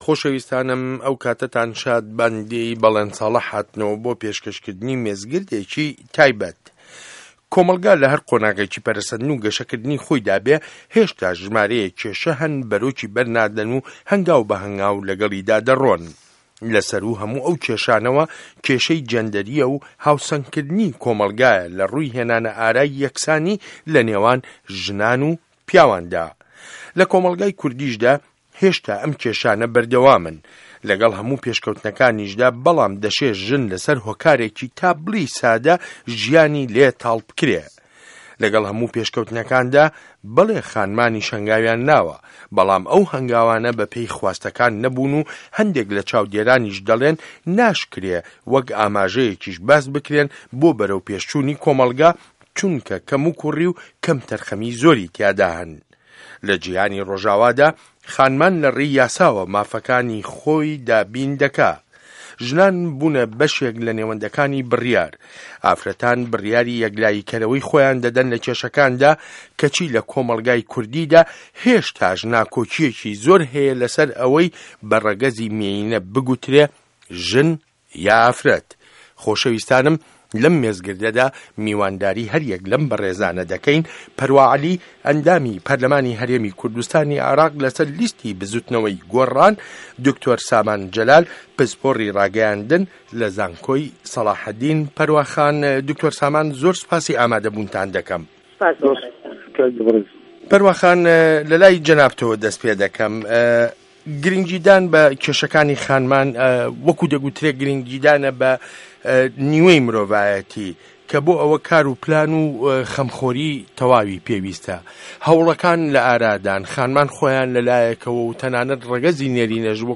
مێزگرد : خانمان و نه‌گه‌یشتن به‌ نێوه‌نده‌کانی بڕیار